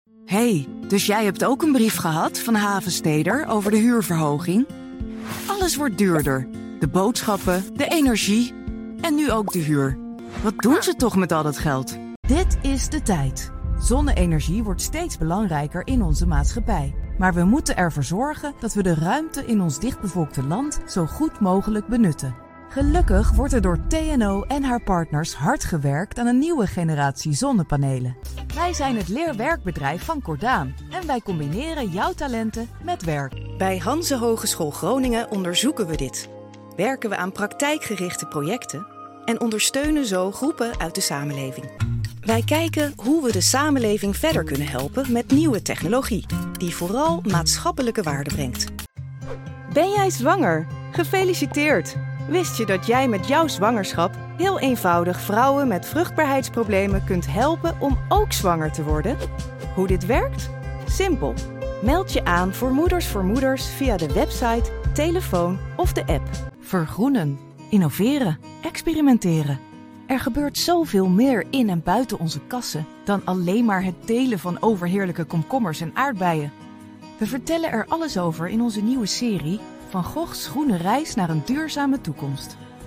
Certified in Estill Voice.
Sprechprobe: Industrie (Muttersprache):
Corporate demo long 2025 .mp3